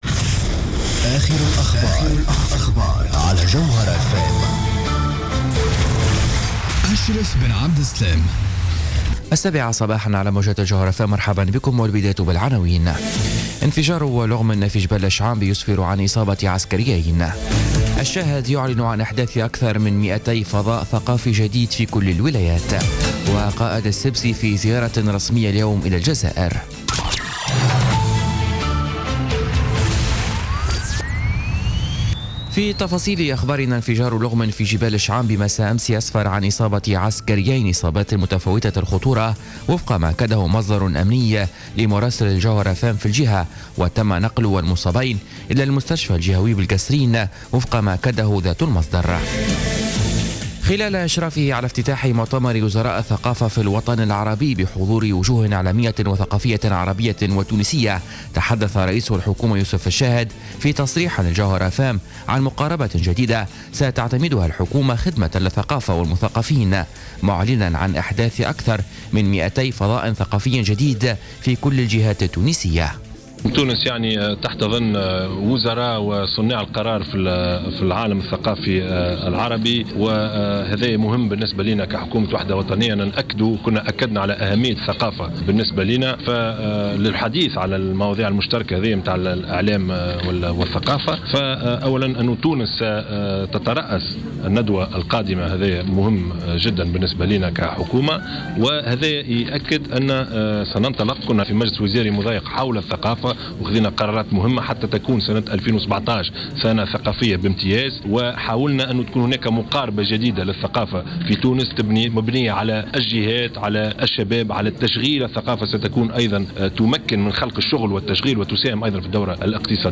نشرة أخبار السابعة صباحا ليوم الخميس 15 ديسمبر 2016